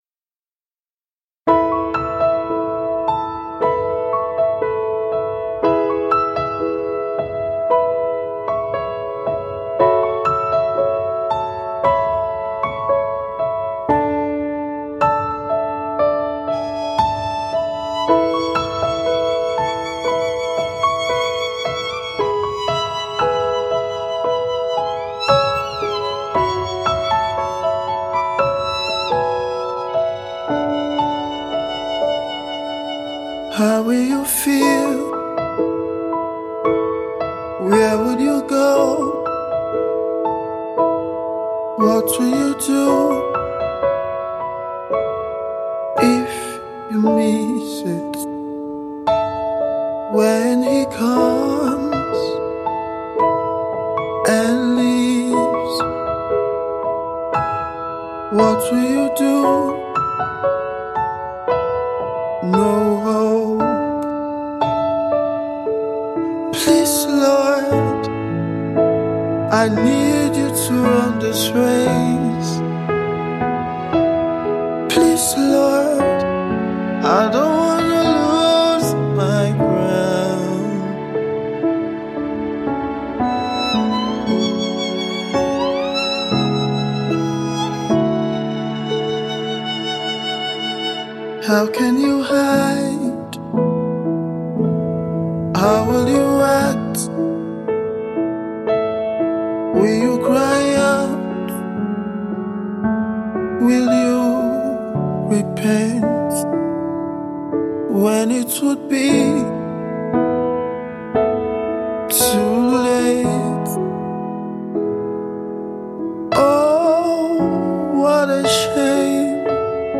multi instrumentalist and gospel artiste